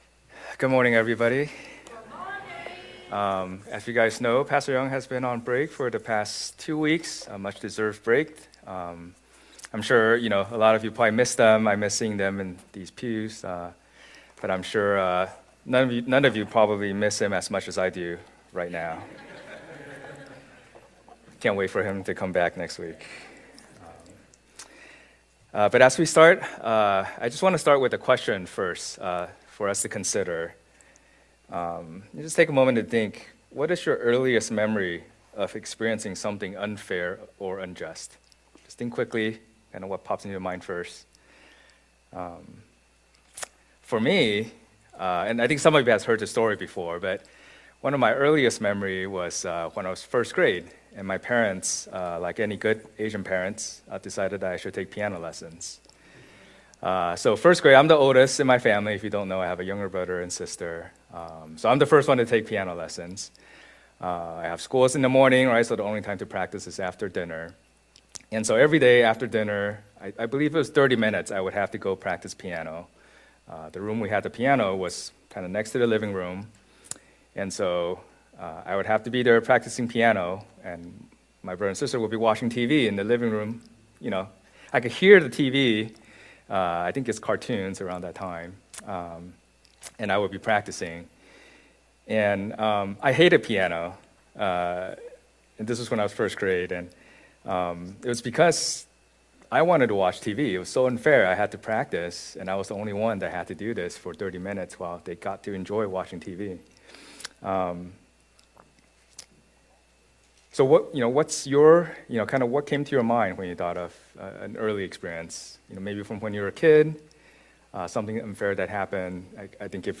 Elders Passage: Micah 6:1-8 Service Type: Lord's Day « Parable of the Sower